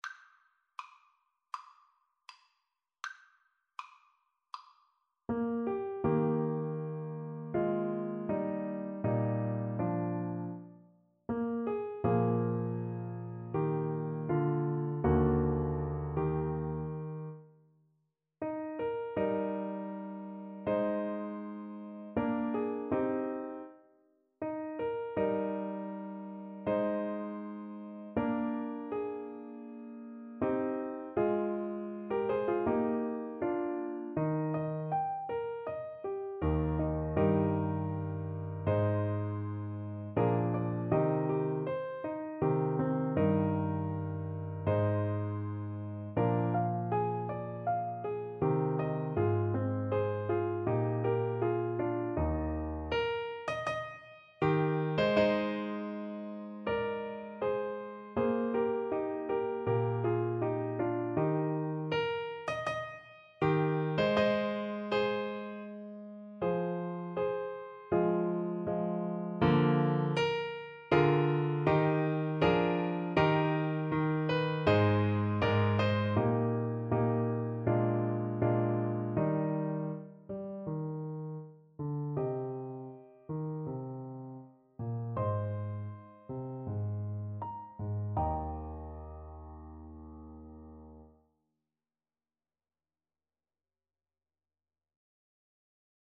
4/4 (View more 4/4 Music)
Andante
Classical (View more Classical Saxophone Music)